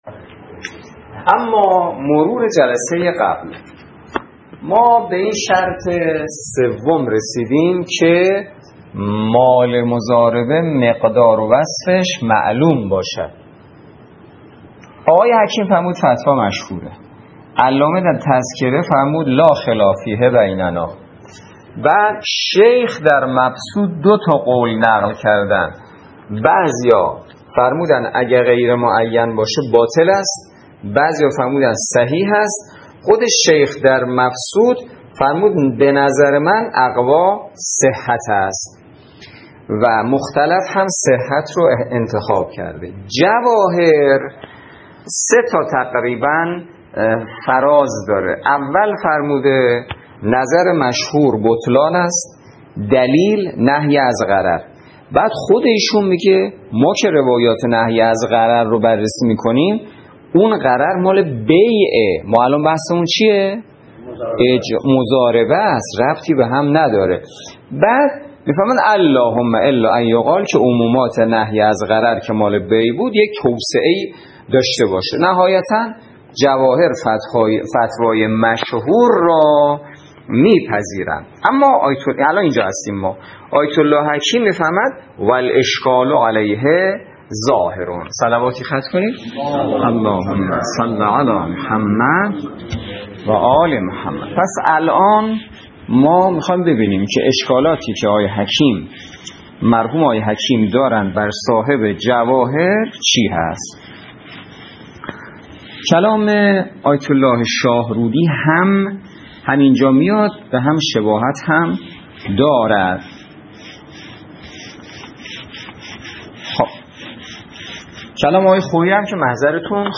درس فقه